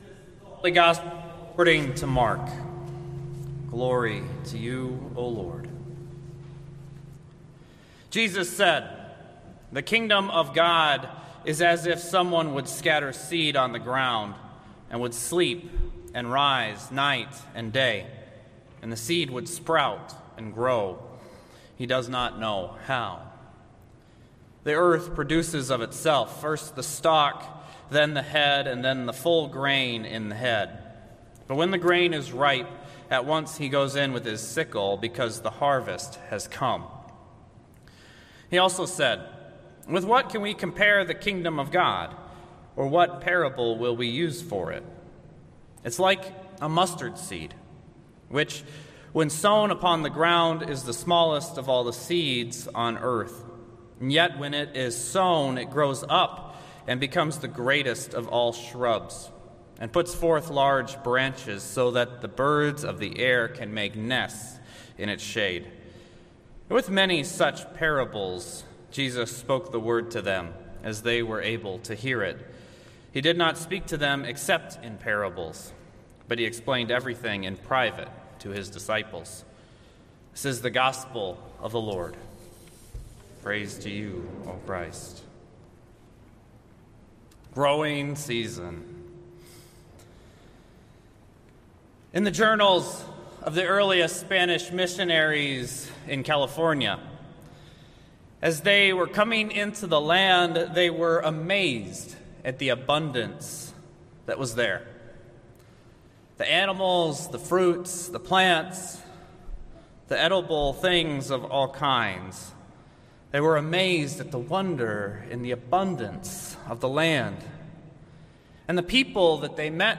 Worship Services | Christ The King Lutheran Church